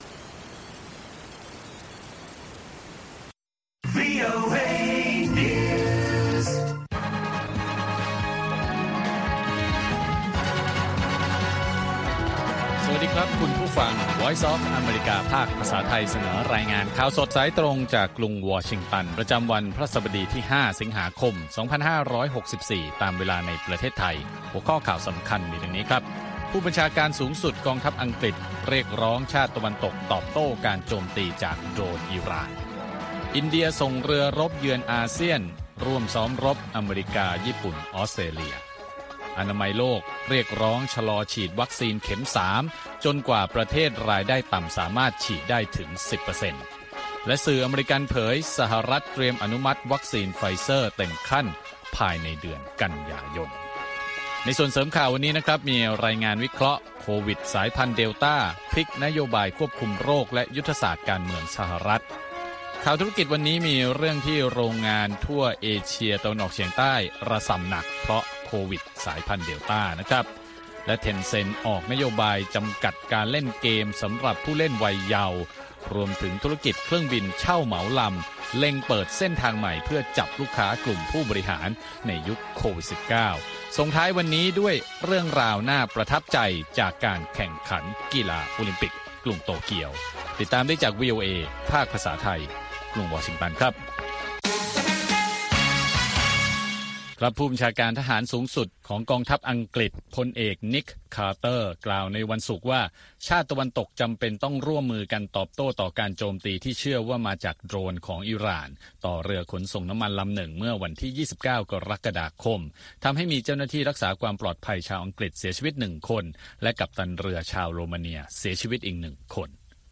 ข่าวสดสายตรงจากวีโอเอ วันพฤหัสบดี ที่ 5 สิงหาคม 2564